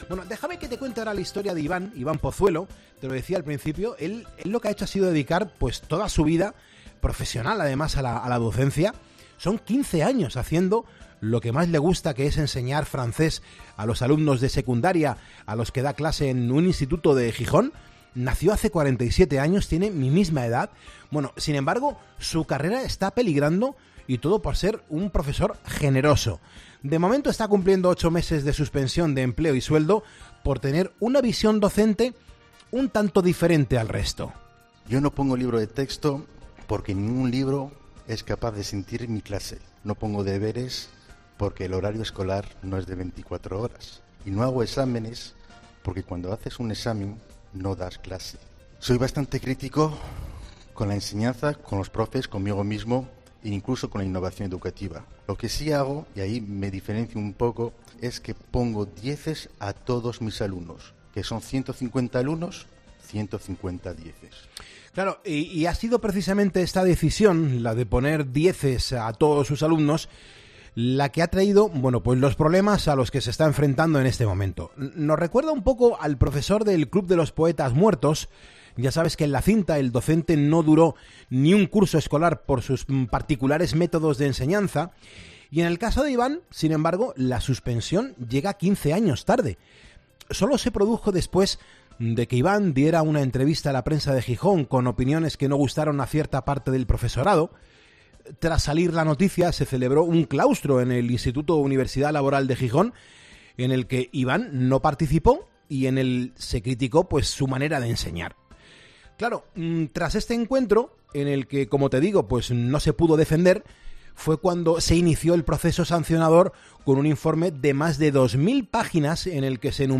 En Poniendo las calles hemos podido hablar con él y nos ha explicado porqué utiliza este poco ortodoxo método de enseñanza.